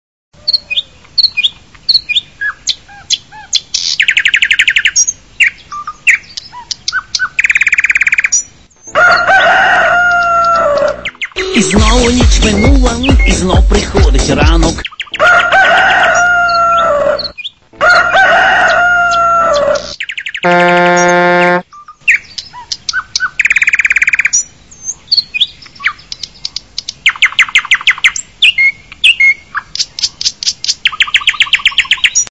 Тип: рінгтони
budulnuk_ptashku_pivni.mp3 [130.35 Kb] (Завантажень: 78)